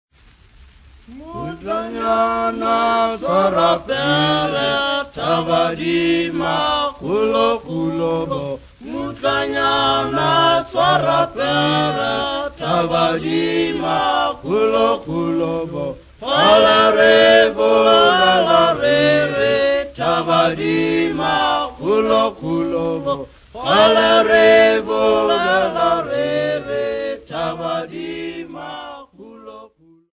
Aliwal North Sesotho Choir
Dance music
Field recordings
unaccompanied male voive wedding song